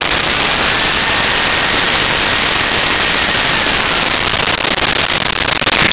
Blender On Blend
Blender on Blend.wav